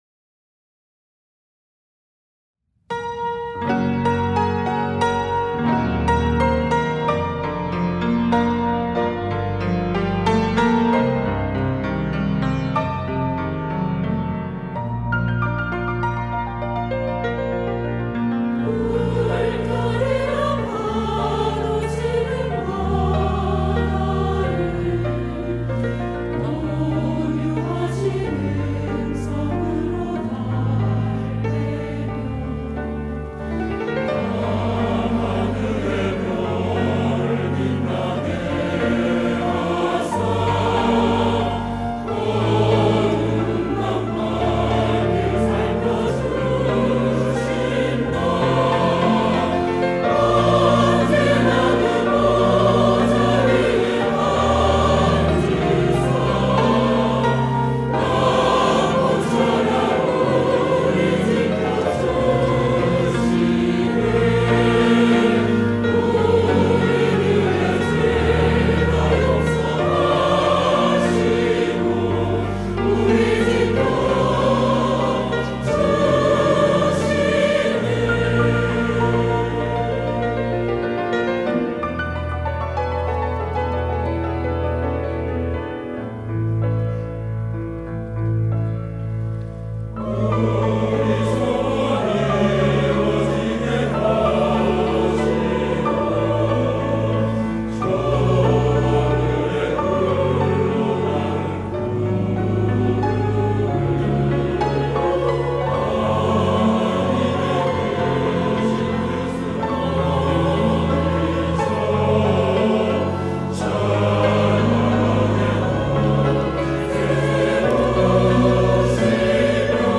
시온(주일1부) - 주님
찬양대